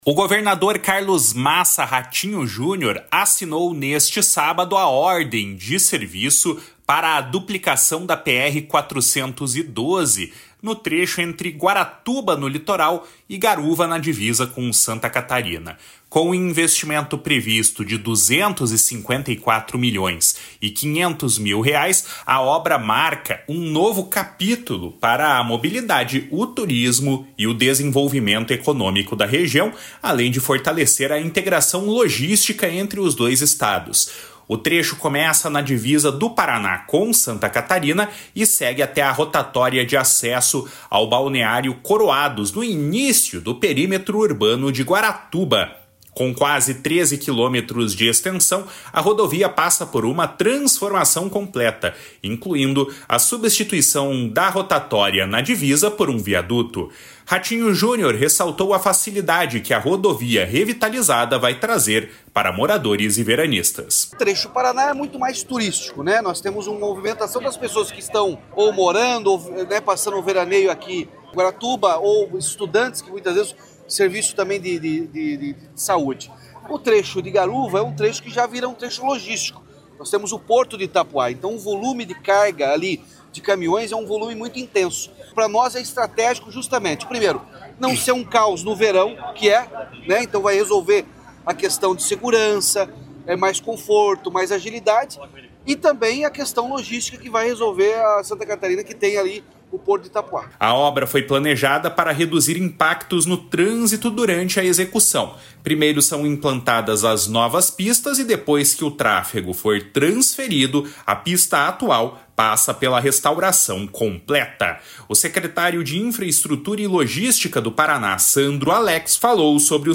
// SONORA RATINHO JUNIOR //
// SONORA JORGINHO MELLO //